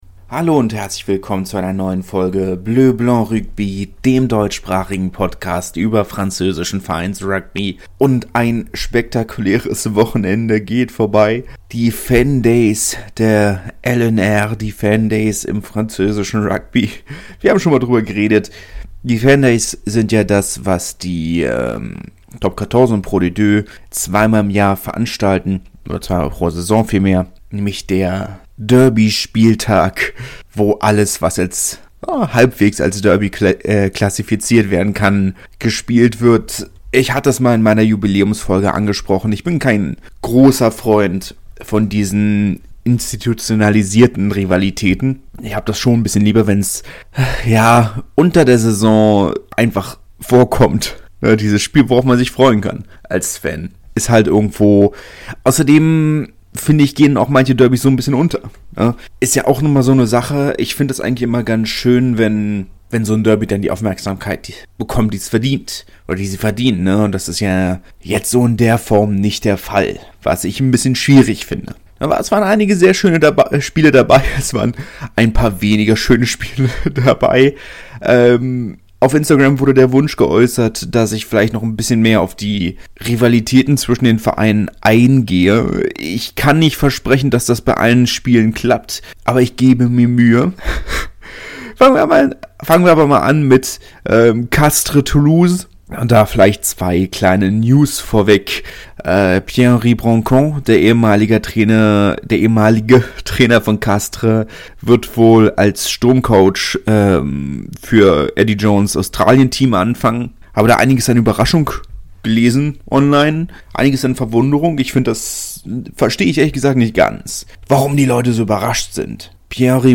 In drei Etappen aufgenommen und mit vielen Versprechern, aber halbwegs pünktlich!